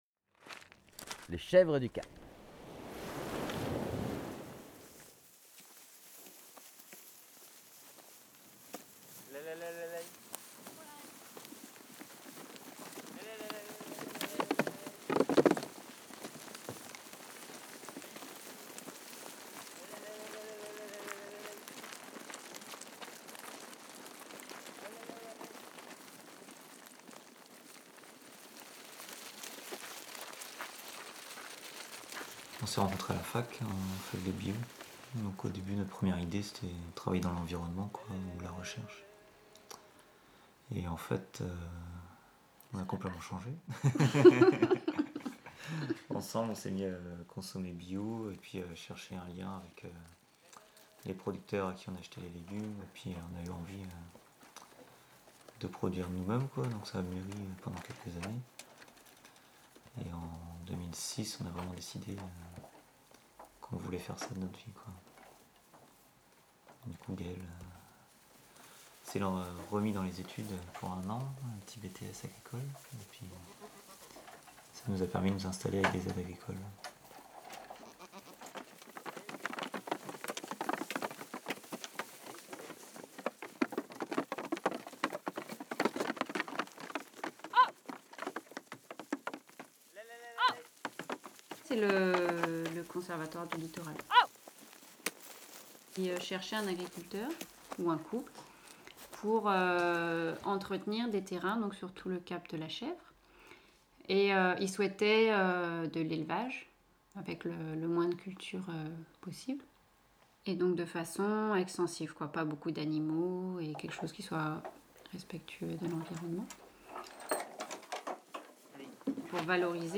Une friandise sonore issue de Territoires Sonores , un projet collaboratif dont le but est de faire découvrir le territoire du Cap de la Chèvre en utilisant le média sonore.
chevres_du_cap.mp3